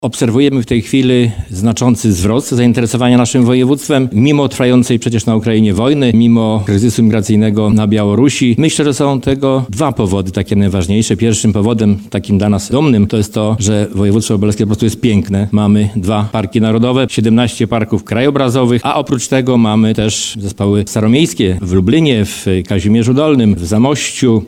Wydarzenie otworzył Zdzisław Szwed, członek zarządu województwa lubelskiego: